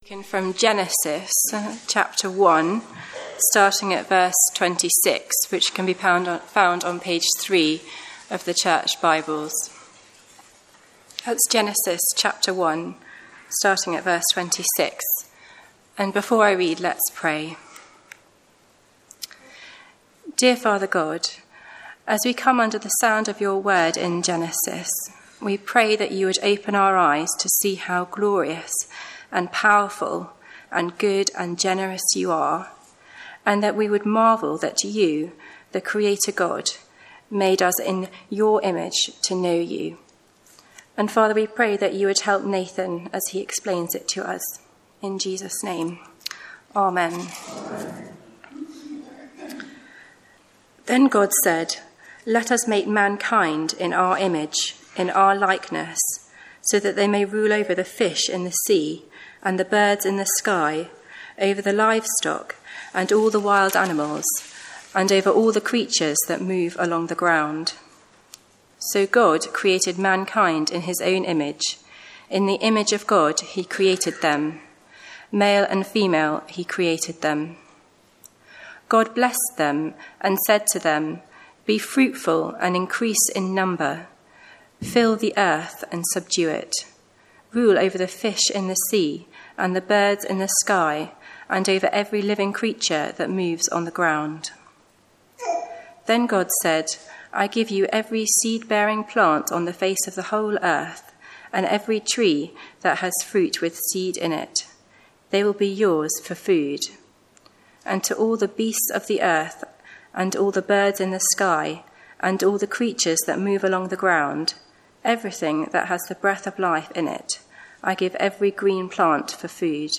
Sermons Archive - Page 78 of 188 - All Saints Preston
1 John 2:1-2 – Guest Service